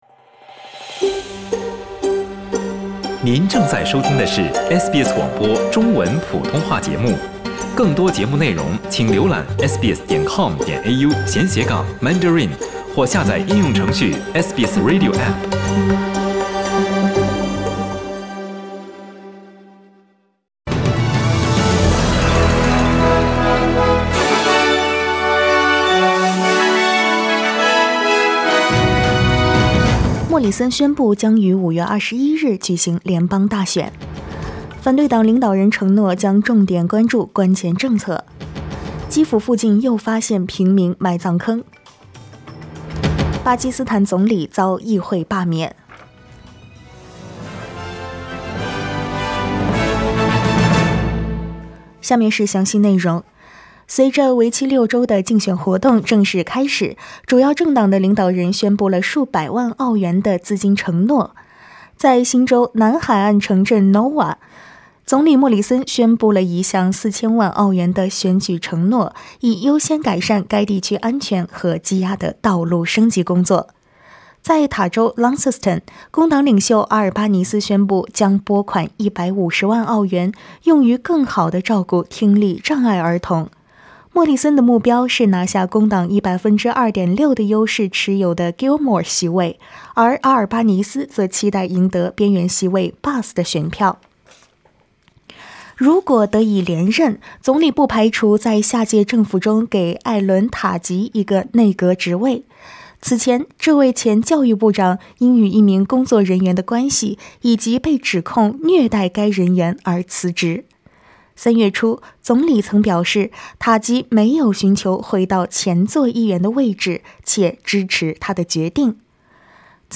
SBS早新闻（4月11日）
SBS Mandarin morning news Source: Getty Images